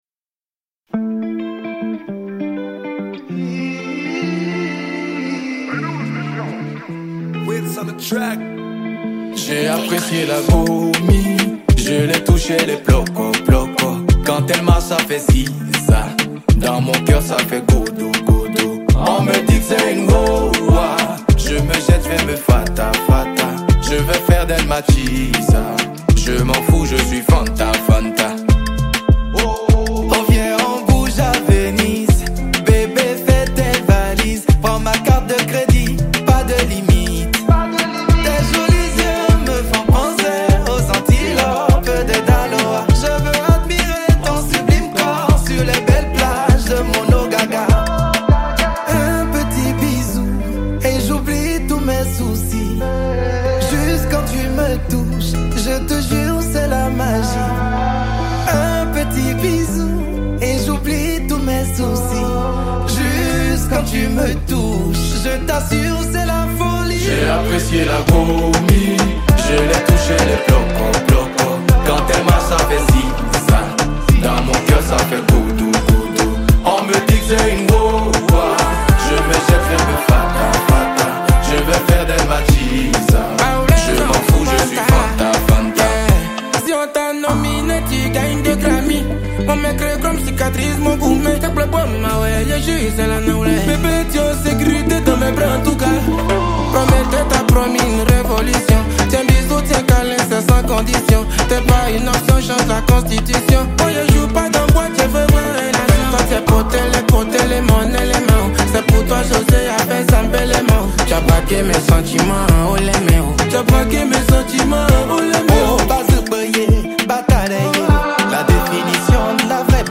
| Afrobeat